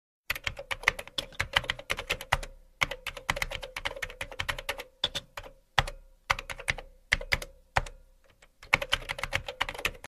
Escribiendo en el teclado: Efectos de sonido tecnología
La nitidez y la fidelidad de este efecto de sonido te ofrecen una gran versatilidad creativa.
Este efecto de sonido ha sido grabado para capturar la naturalidad del proceso de escritura en un teclado, proporcionando un sonido claro y distintivo que se integrará perfectamente en tus proyectos.
Tipo: sound_effect
Escribiendo en el teclado.mp3